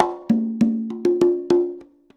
100 CONGAS11.wav